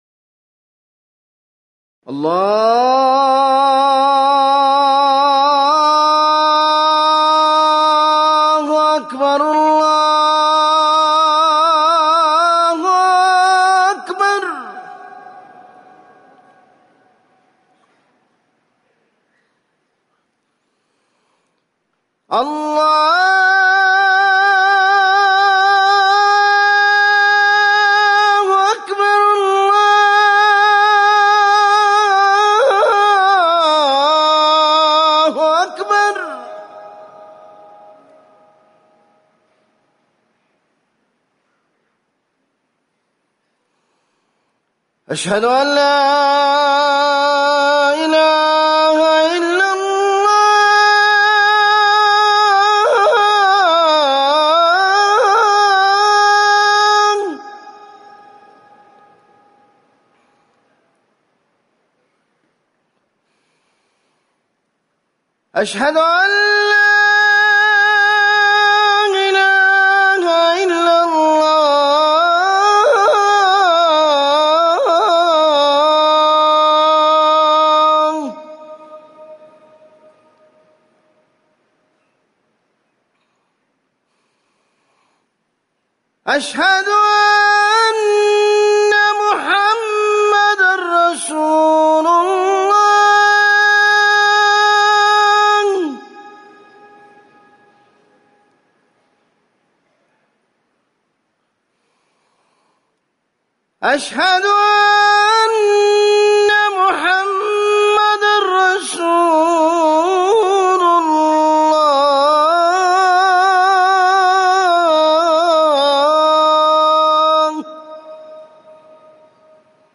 أذان الجمعة الثاني
تاريخ النشر ١٩ صفر ١٤٤١ هـ المكان: المسجد النبوي الشيخ